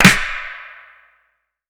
Toxic ClapSnare2.wav